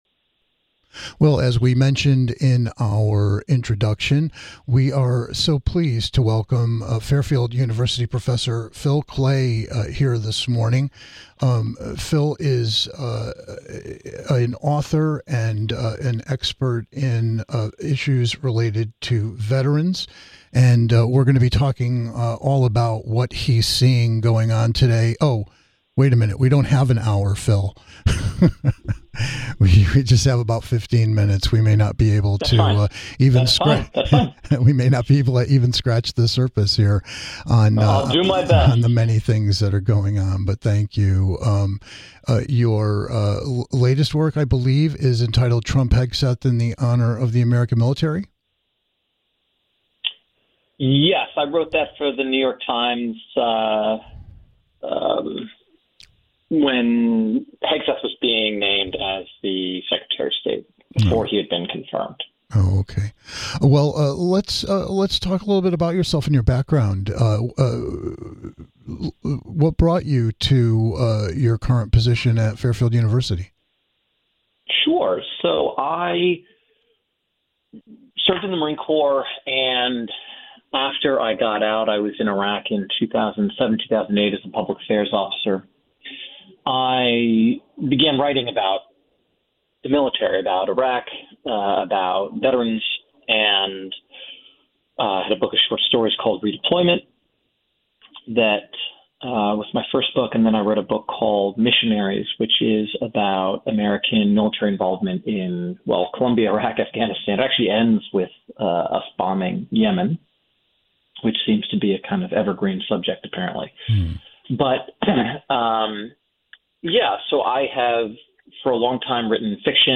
Check out our conversation with Marine, author and Fairfield University Professor Phil Klay about the opportunities for fellow veterans interested in creative writing - and what the university offers to those who served, or anyone interested in its unique MFA program.